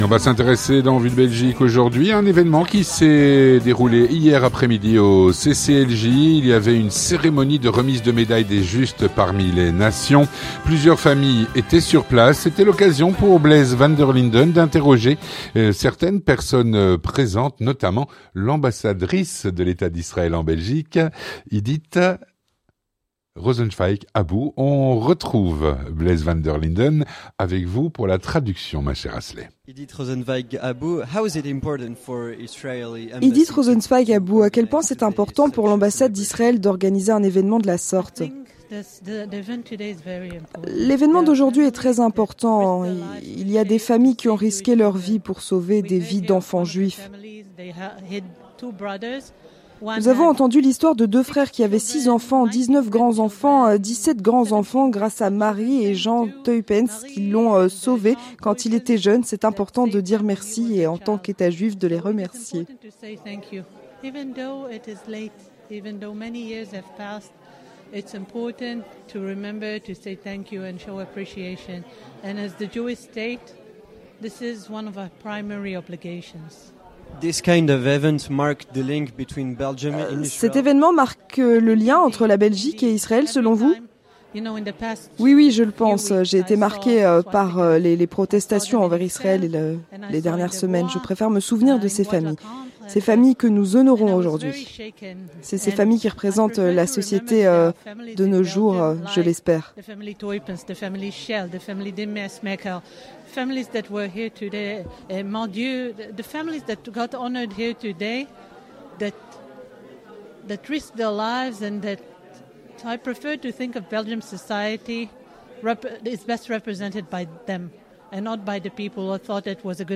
Cérémonie pour des Justes parmi les nations au CCLJ (01/03/2023)
Hier après-midi au CCLJ avait lieu une cérémonie de remise de médaille des Justes parmi les nations. Plusieurs familles étaient sur place.